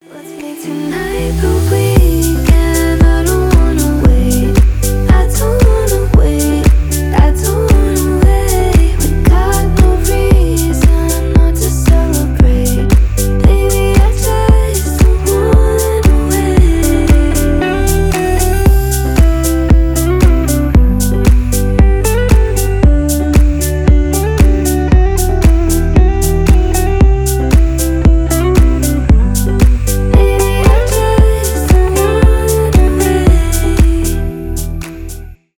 chill house